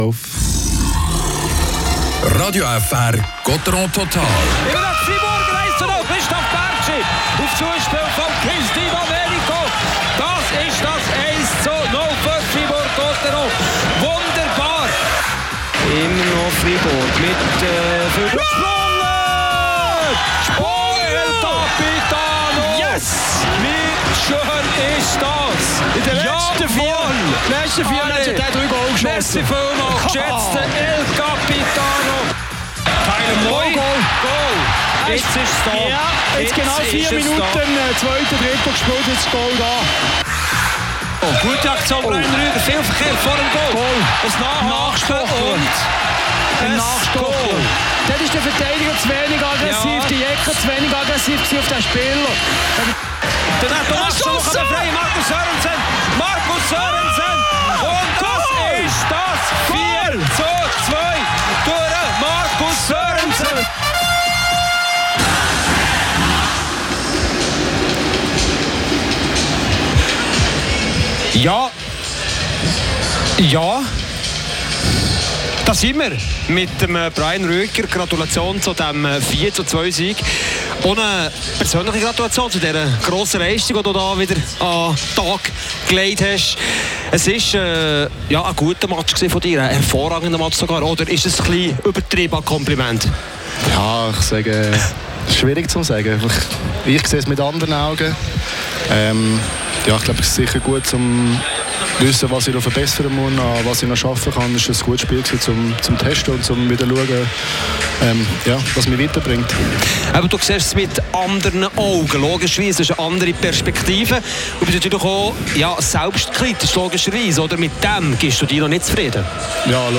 Gottéron schlägt Rapperswil auswärts mit 4:2. Es ist bereits der achte Sieg am Stück. Interview